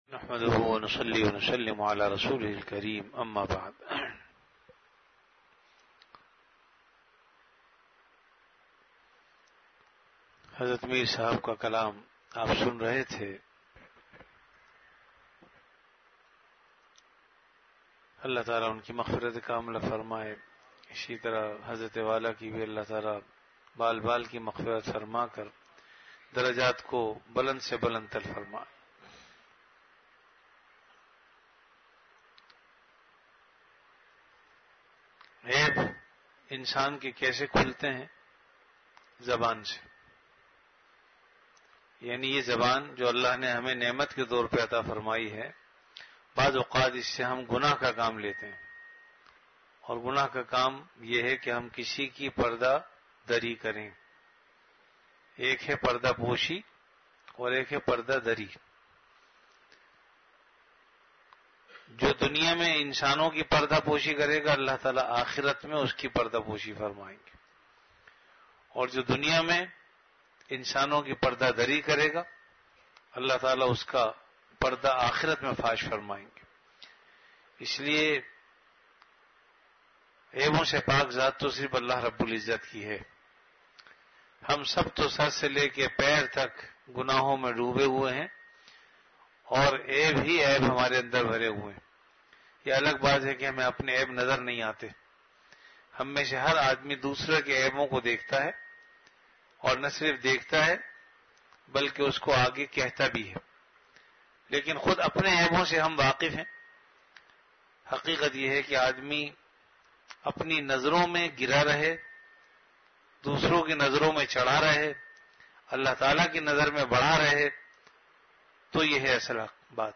on Majlis-e-Zikr.